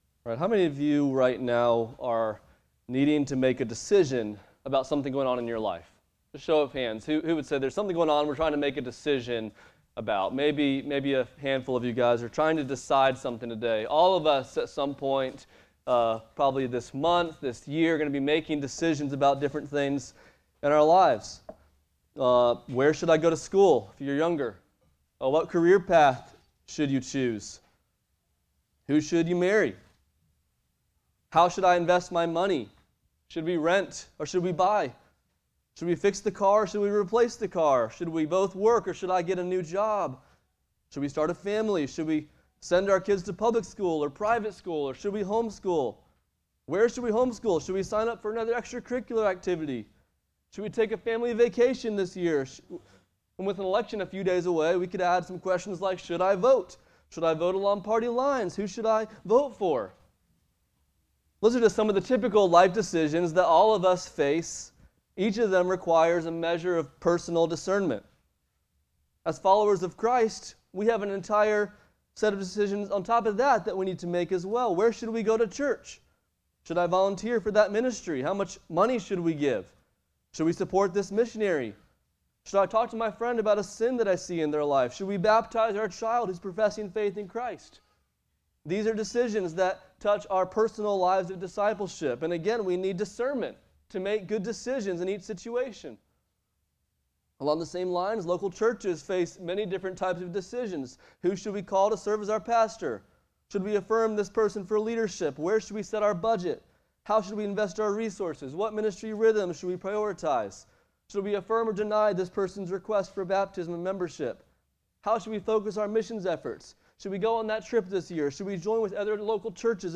Acts 15:36-16:10 Service Type: Sunday Morning « Acts 2:1-13 When in Rome »